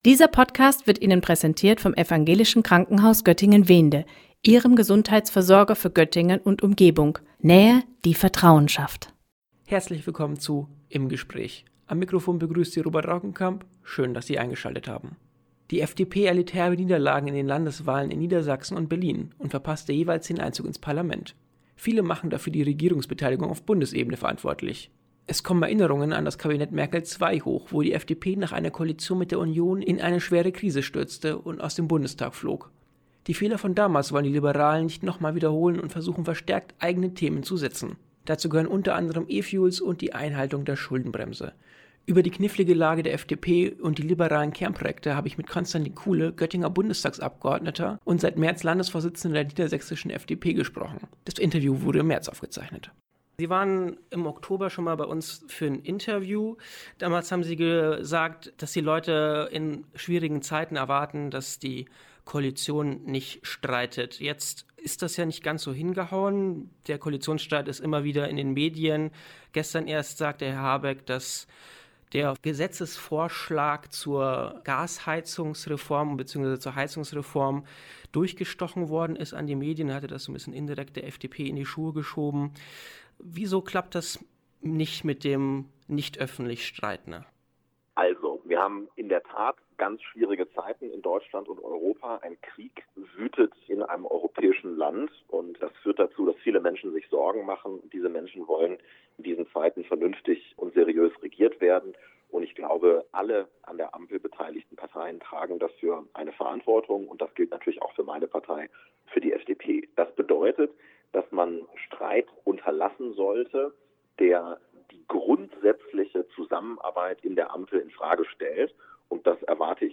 Konstantin Kuhle im Gespräch über die Lage der FDP, E-Fuels und Schuldenbremse